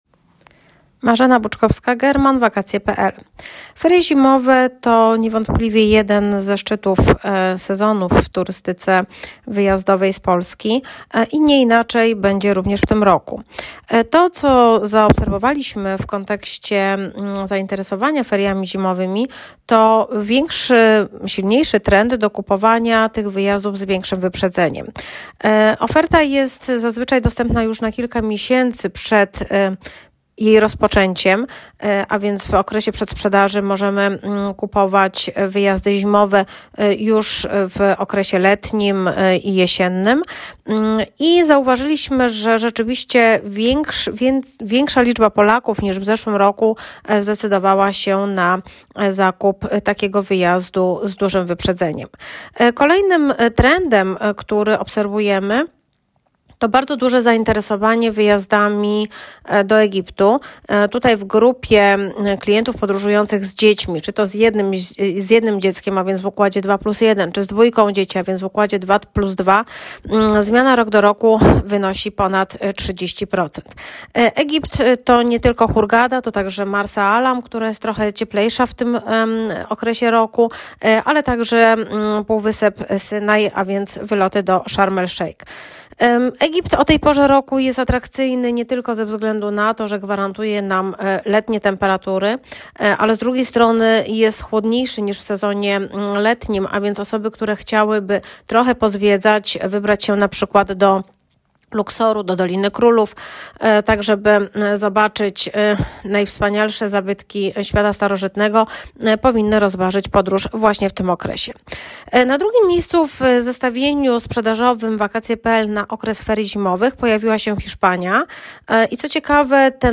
Komentarz ekspercki: Coraz więcej Polaków wybiera ferie w ciepłych krajach – oferta biur podróży większa o 30 proc.